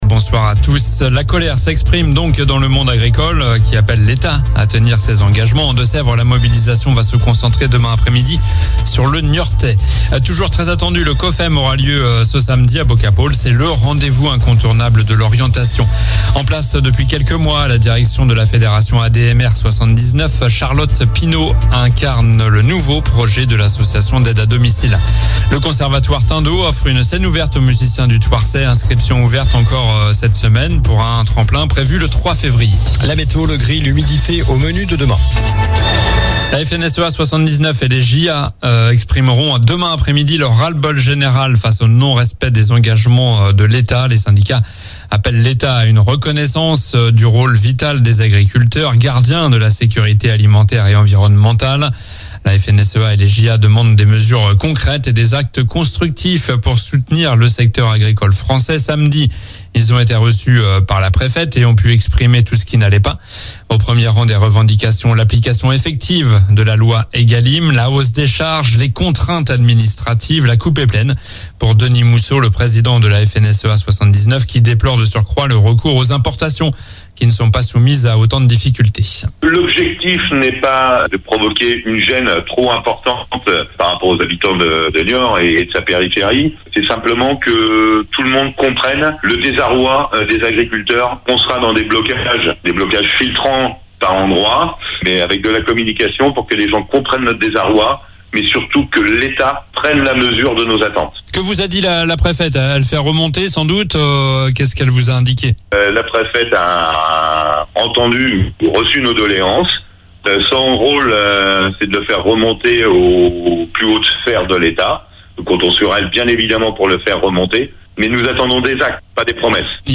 Journal du lundi 22 janvier (soir)